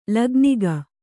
♪ lAgnika